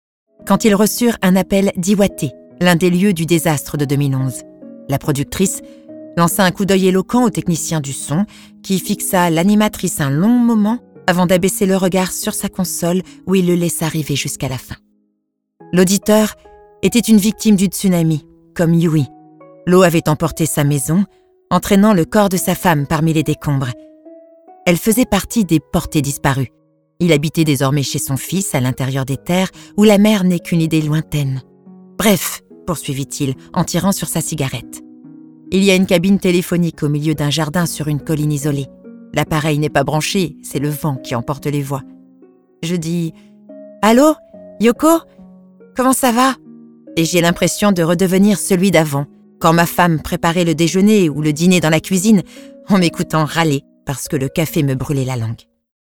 comédienne voix off